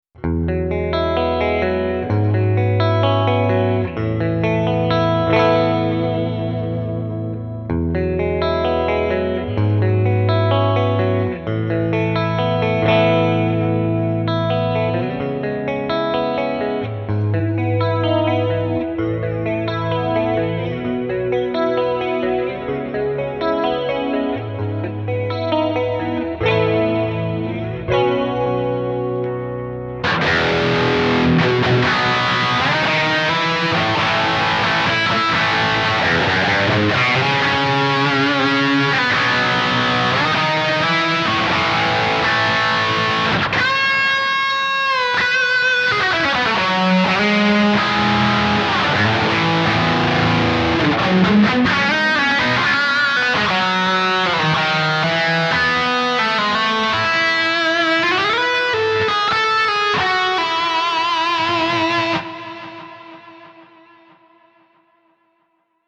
This Amp Clone rig pack is made from a SLO II Synergy Module with a matching cab.
RAW AUDIO CLIPS ONLY, NO POST-PROCESSING EFFECTS